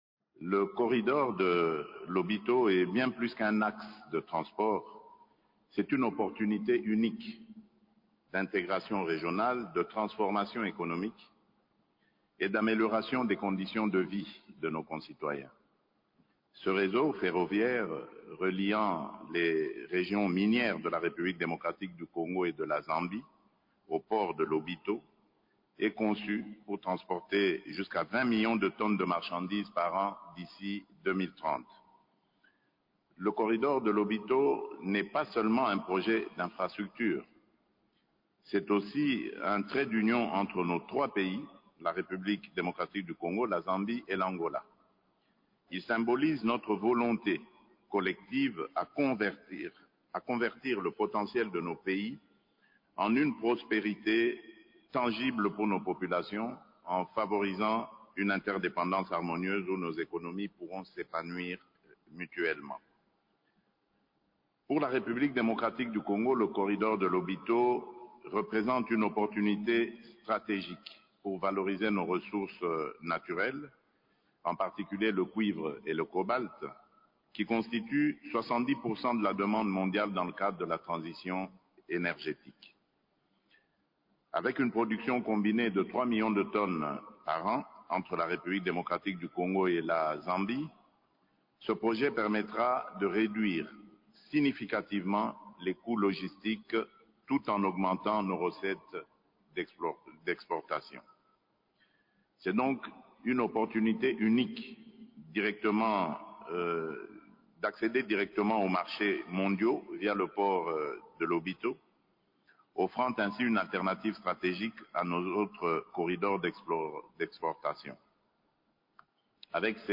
Cette rencontre multilatérale s’est tenue à Lobito, province de Benguela (Angola) autour des présidents Felix Tshisekedi de la RDC, Joe Biden des USA, Joao Lourenco de l’Angola, Hakainde Hichlema de la Zambie ainsi que le vice-président de la Tanzanie.
Ecoutez ici le discours de Felix Tshisekedi lors de cette réunion :
felix_tshisekedi-lobito.mp3